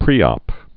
(prēŏp) Informal